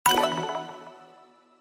menu-charts-click.mp3